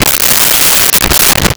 Kiss.wav